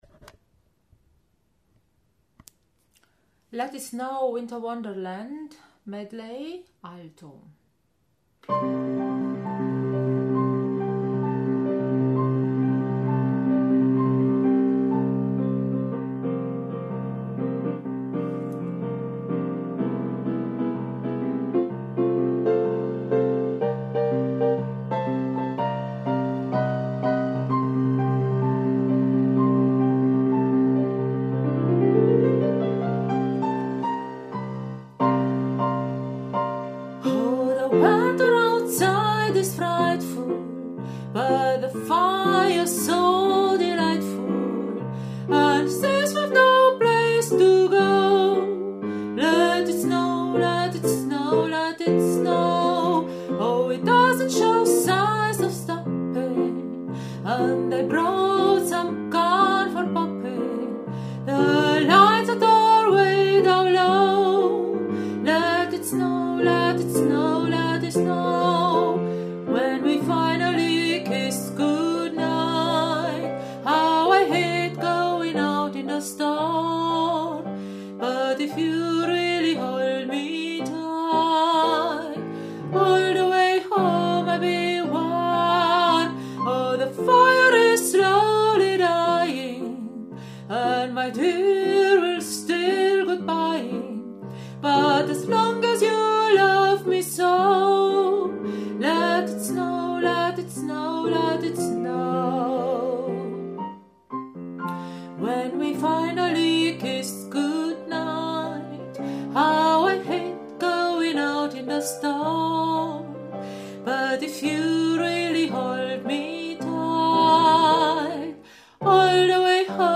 Let it snow / Winter wonderland – Alto
Let-is-Snow-Medley-Alto.mp3